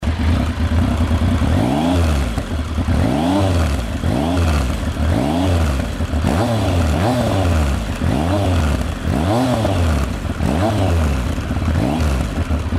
Mise en route : l'est beau le son d'origine, rien à changer !